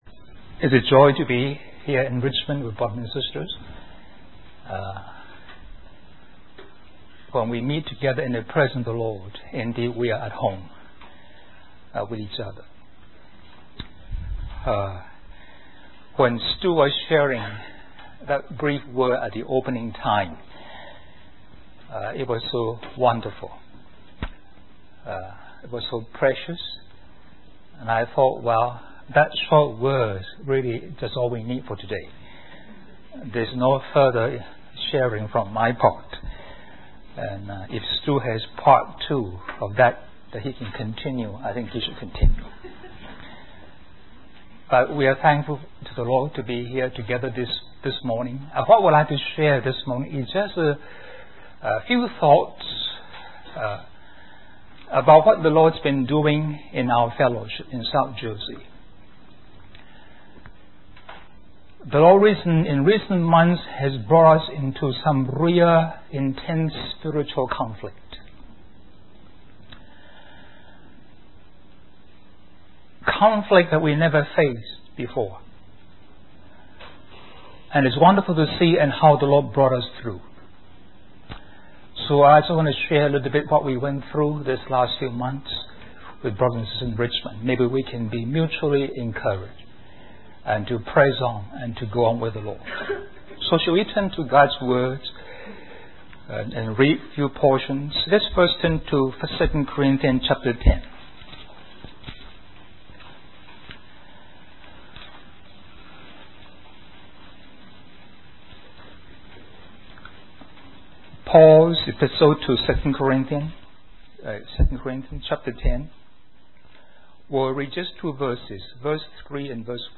In this sermon, the speaker discusses the concept of learning from negative experiences in order to understand what is right.